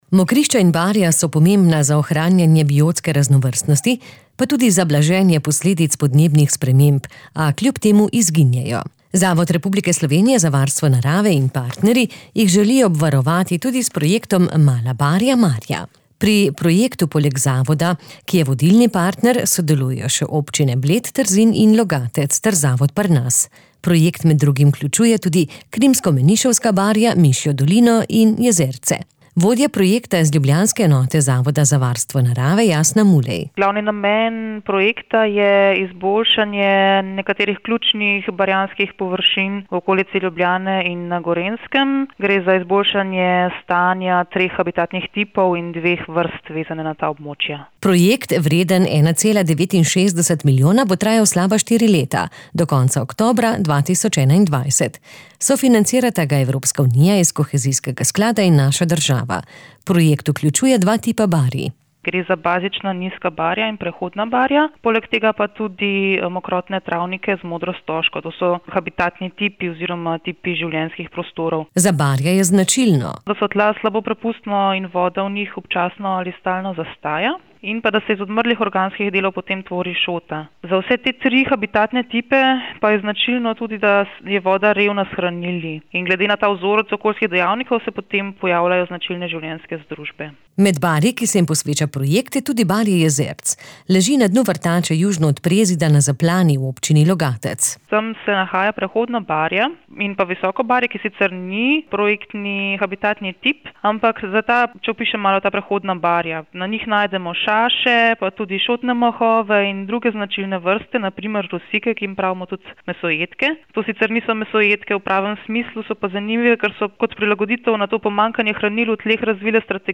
Pogovarjali smo se